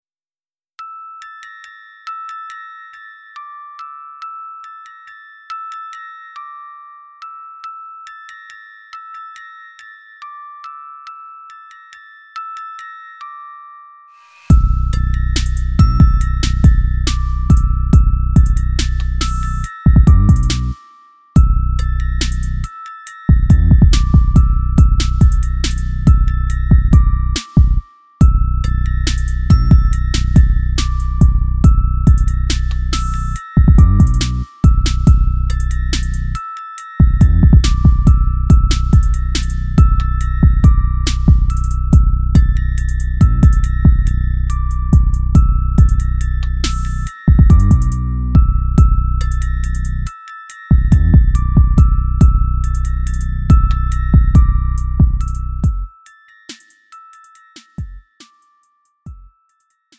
음정 원키 4:11
장르 가요 구분 Lite MR